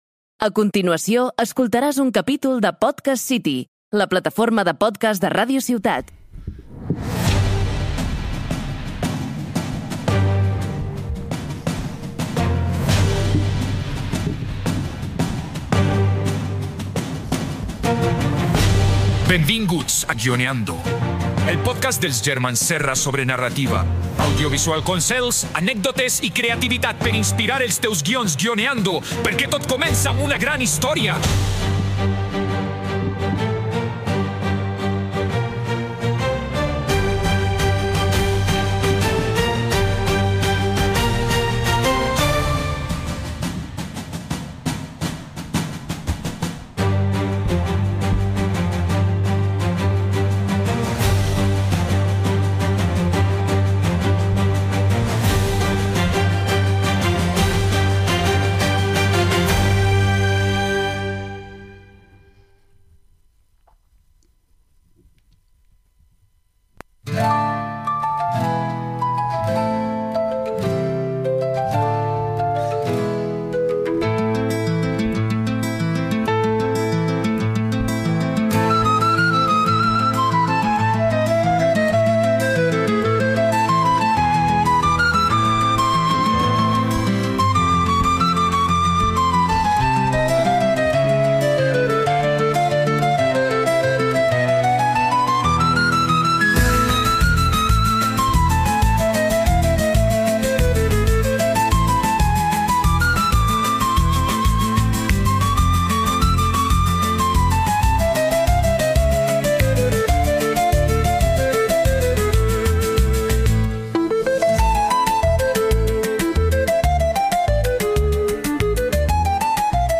amb humor i complicitat